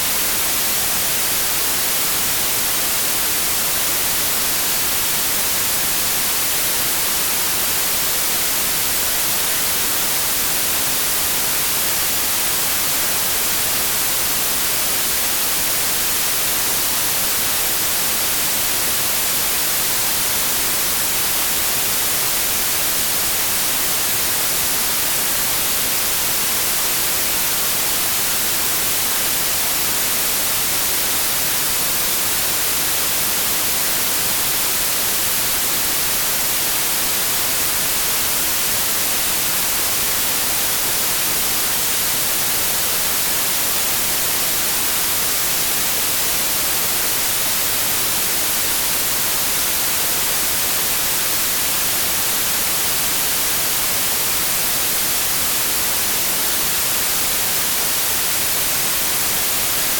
White, Pink and Brown noise, digitally crafted to imitate sounds from across the Land of the Long White Cloud; Aotearoa New Zealand.
Track II - White Noise - The Sound of Tapuae-o-Uenuku (60 secs)